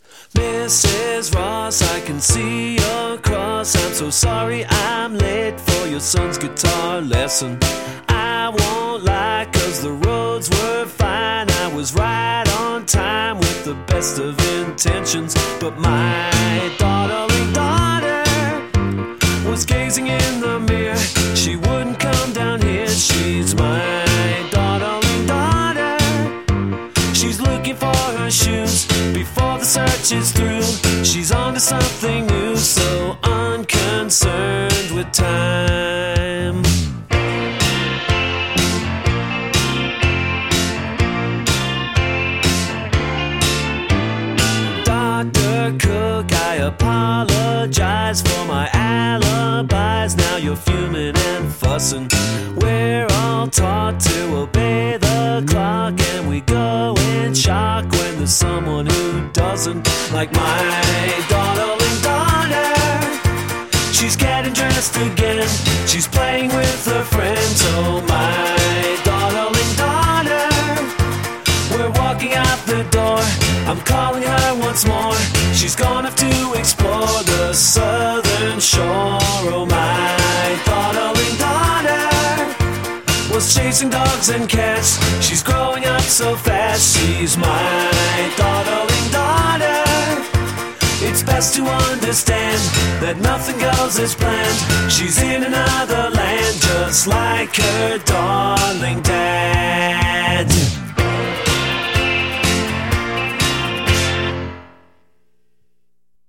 Playful power popper